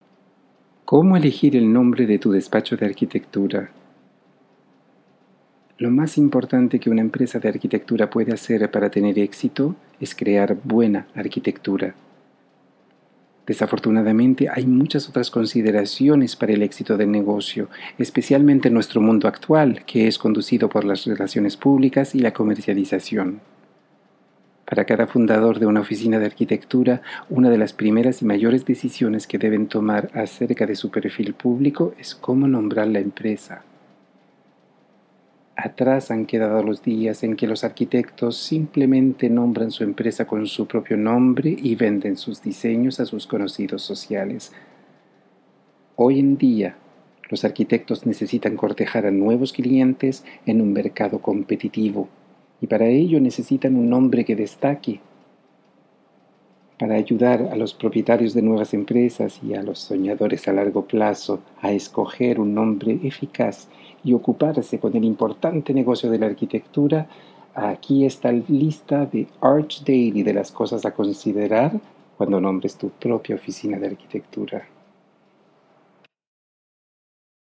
Démo voix espagnol